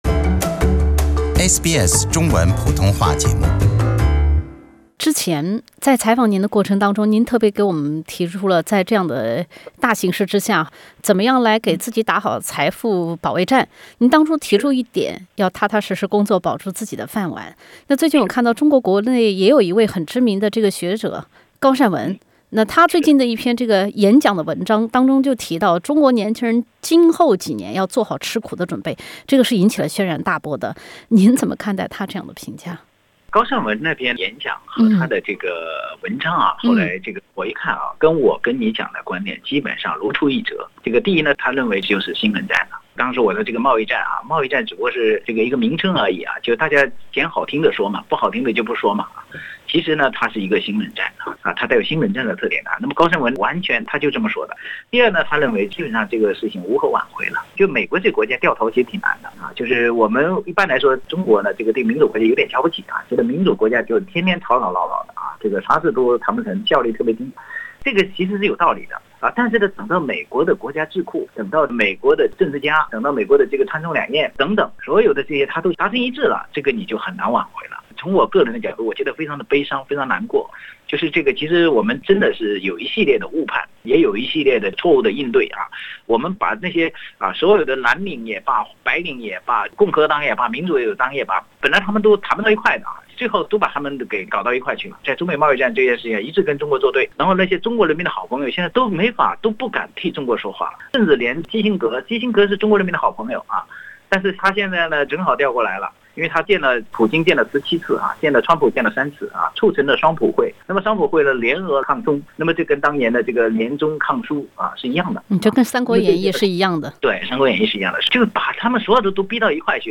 赵晓专访：年轻人准备过苦日子、4500万失业大潮，会在中国出现吗？